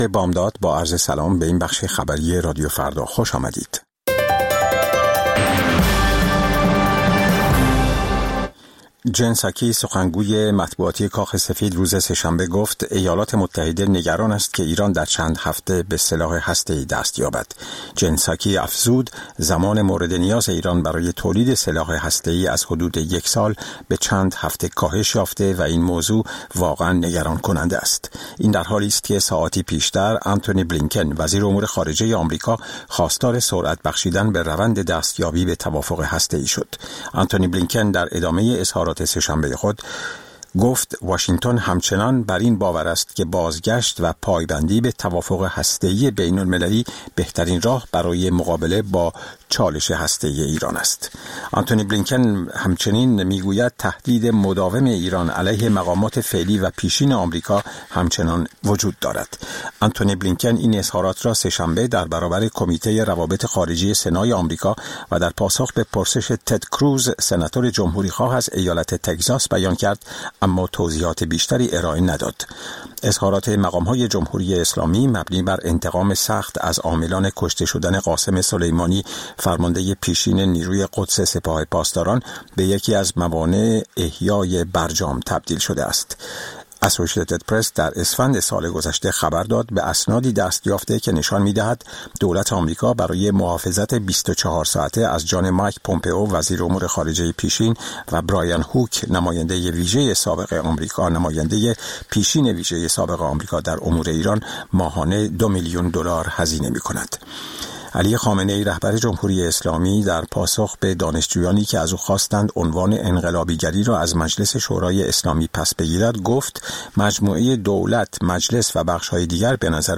سرخط خبرها ۶:۰۰